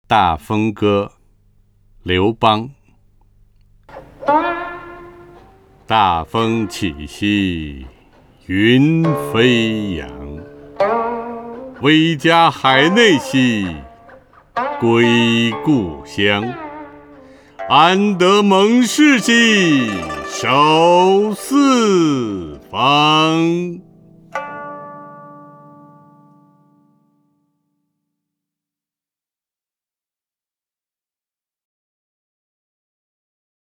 陈铎朗诵：《大风歌》(（西汉）刘邦) (右击另存下载) 大风起兮云飞扬， 威加海内兮归故乡， 安得猛士兮守四方。 （西汉）刘邦 文选 （西汉）刘邦： 陈铎朗诵：《大风歌》(（西汉）刘邦) / 名家朗诵欣赏 陈铎